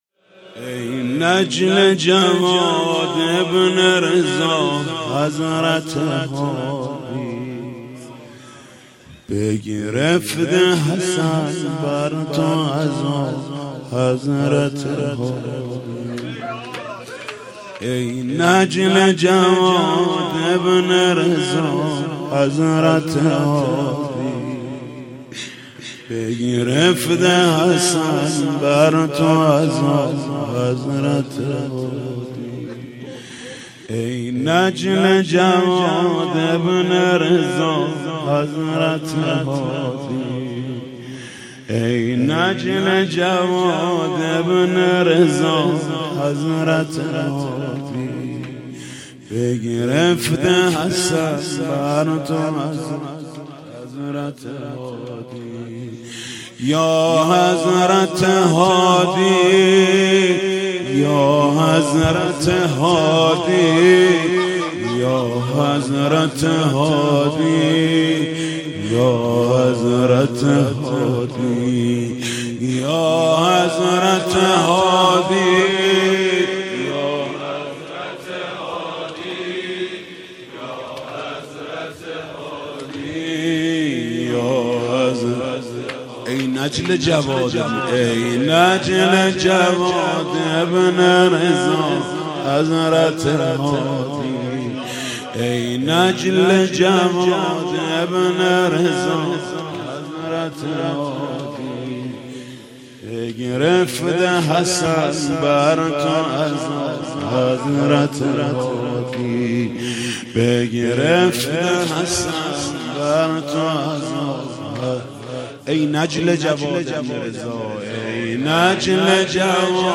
مناسبت : شهادت امام علی‌النقی الهادی علیه‌السلام
مداح : محمود کریمی قالب : روضه